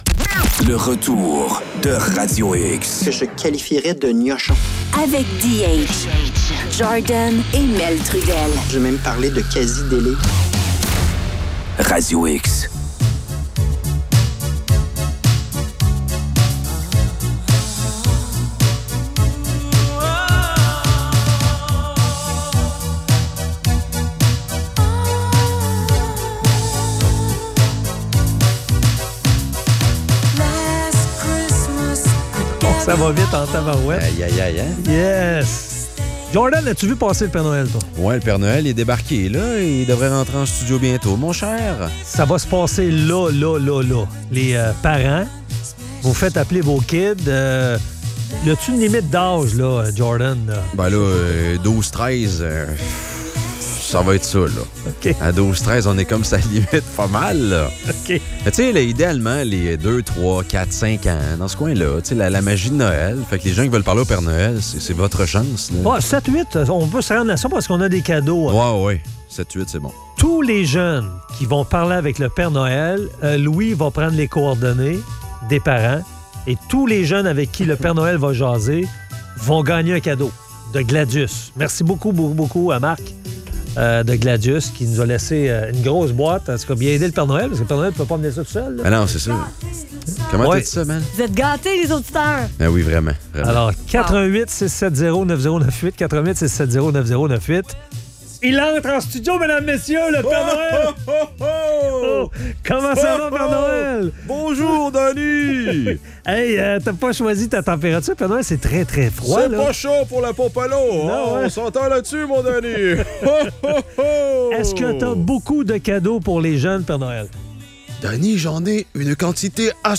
Le "VRAI" Père Noël débarque en studio!